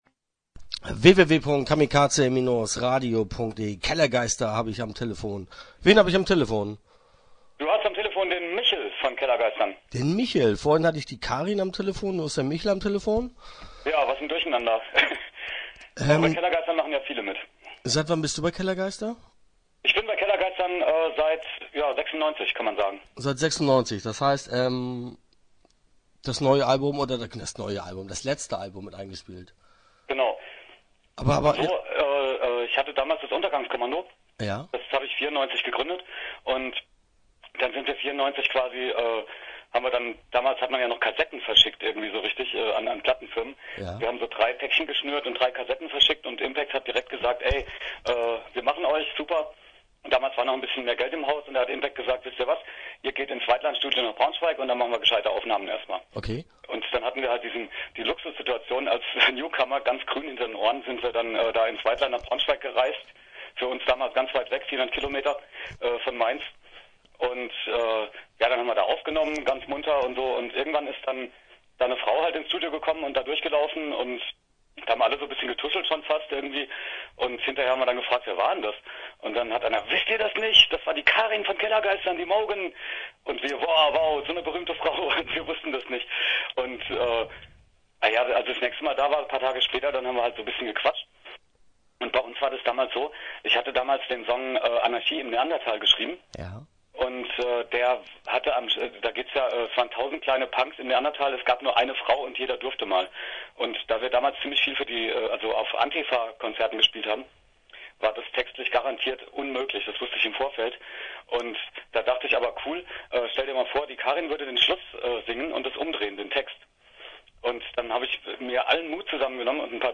Interview Teil 1 (11:58)